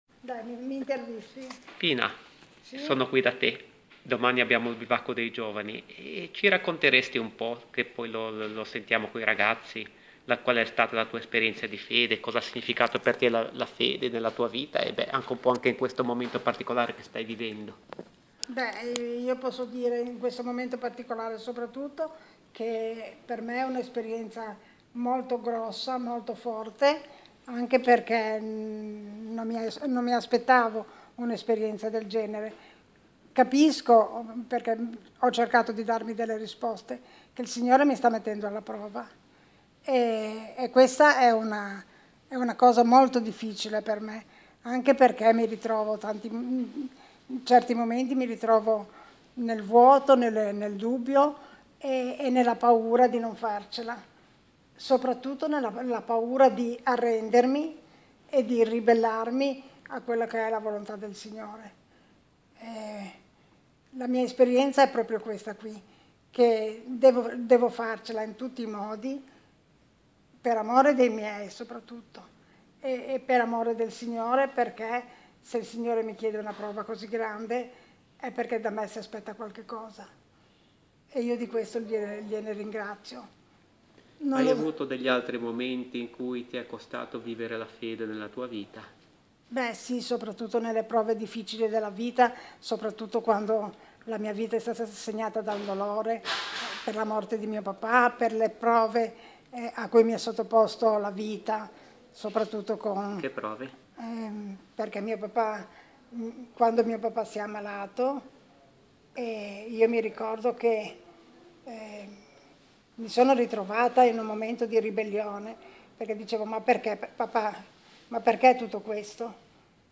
Ne è venuta fuori una specie di intervista, molto bella, che ora è qui a disposizione di chi desidera scaricarsela e ascoltarla: